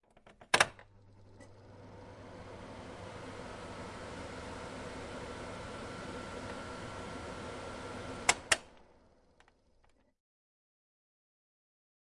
描述：引擎罩